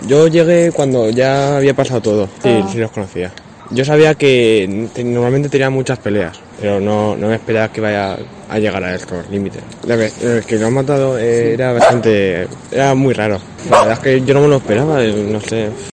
Testimonio de un vecino